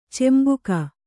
♪ cembuka